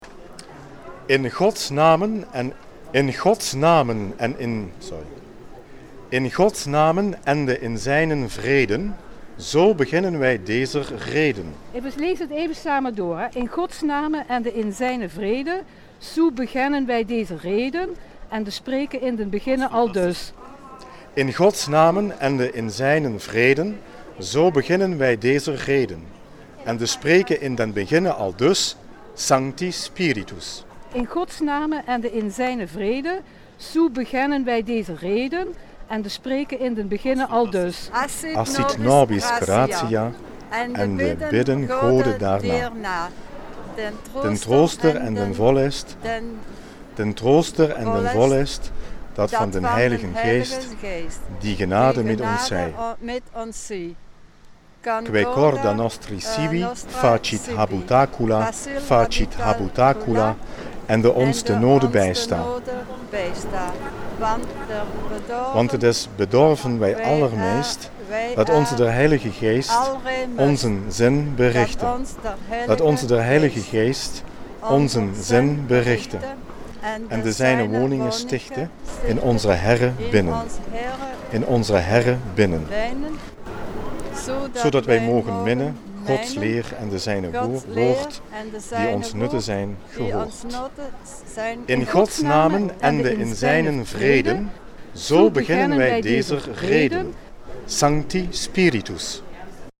Maastricht Servaaslegende op straat